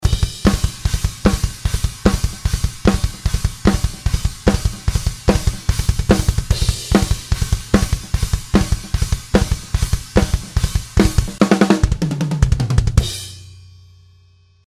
Trotzdem finde ich es schwierig einen Drumtrack zu quantisieren, welcher viele Beckenarbeit in den Overheads hat. Anbei mal ein Soundschnippsel (drums sind roh und nicht quantisiert).